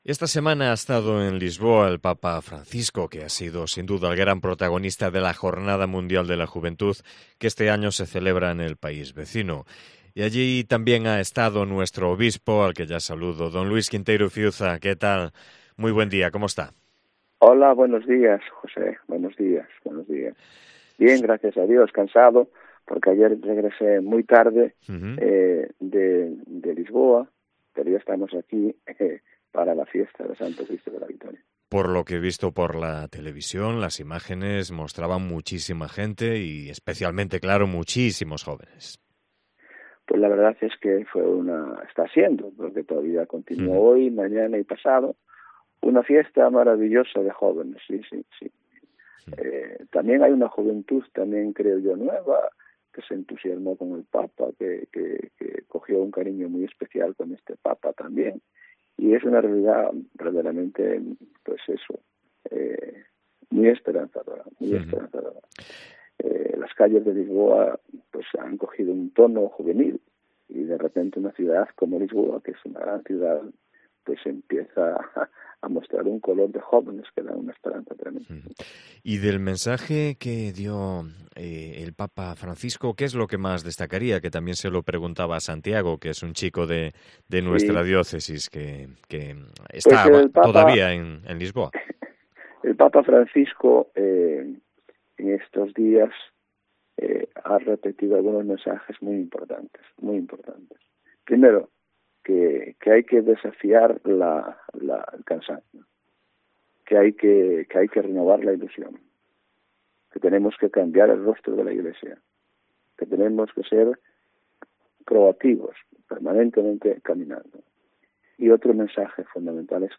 Entrevista al obispo de Tui-Vigo tras su viaje a la JMJ de Lisboa